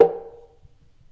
acoustic household percussion sound effect free sound royalty free Memes